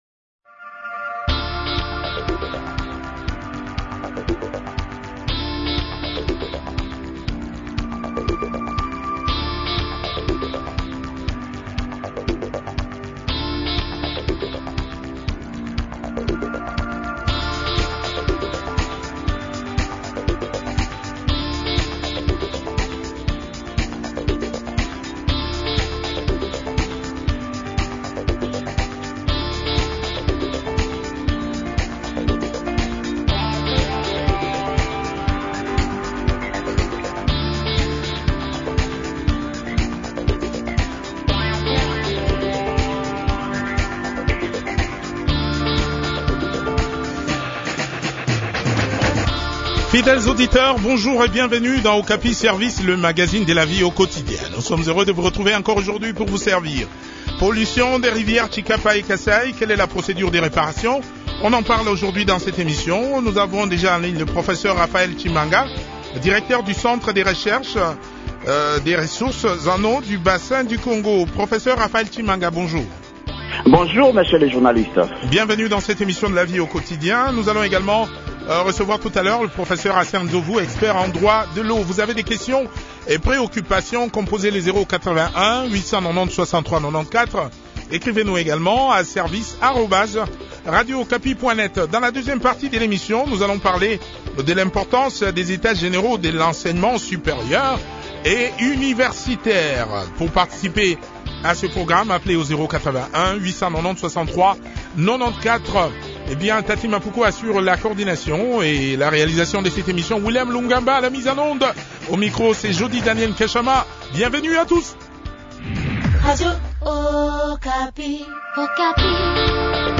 a aussi participé à cette interview.